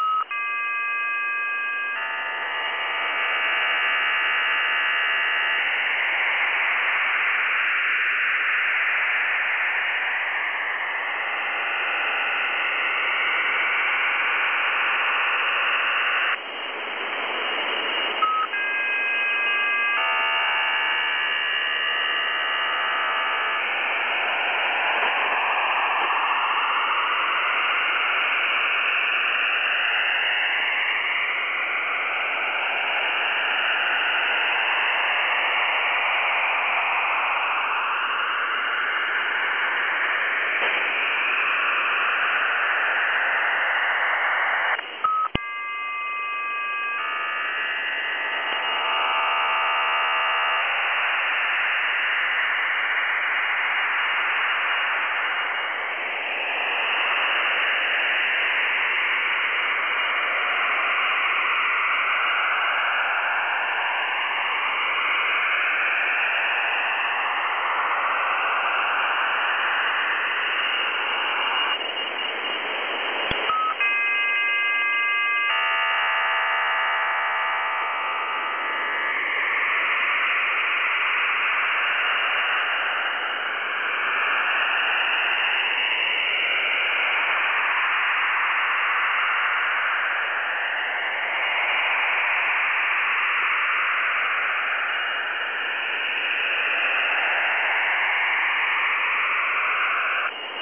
File:110A Israeli Mod 2015-10-12T06-37-06Z 10149.7kHz.mp3 - Signal Identification Wiki